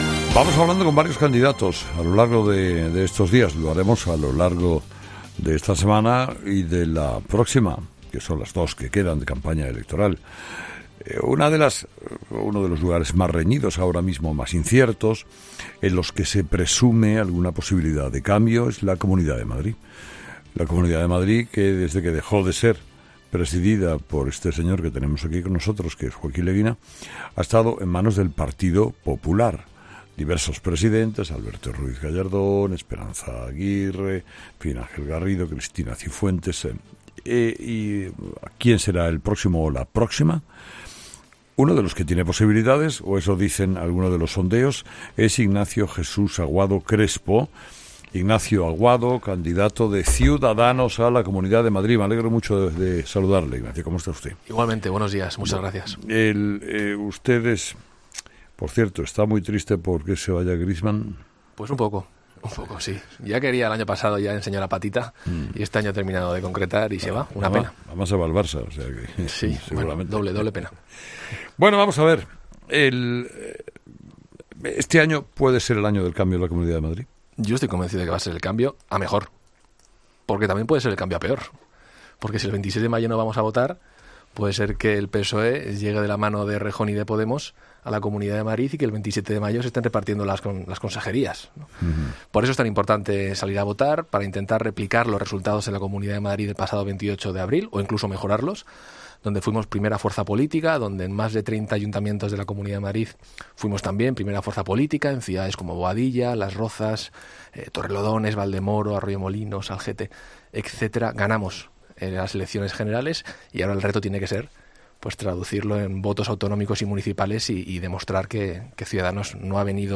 AUDIO: El candidato de Ciudadanos a la Comunidad de Madrid dice en ‘Hererra en COPE’ que impulsará la eliminación definitiva del impuesto de...